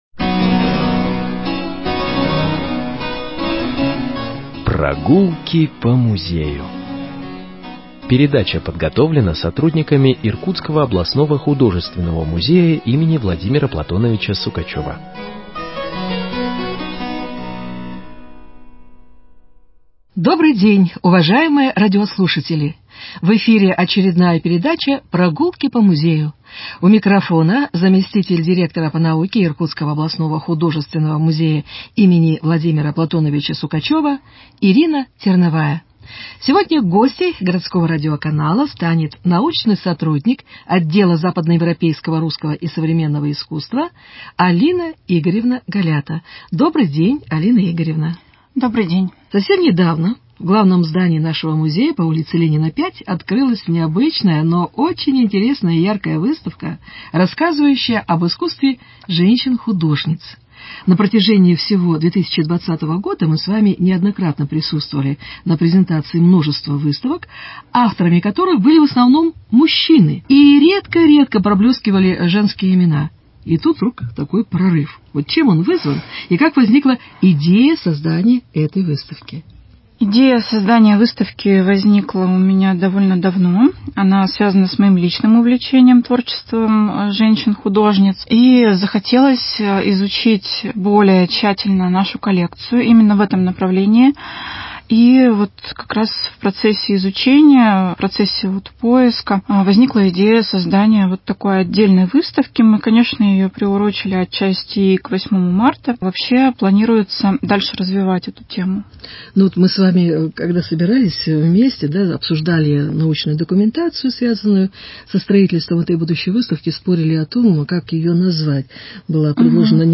Беседа с научным сотрудником отдела западно–европейского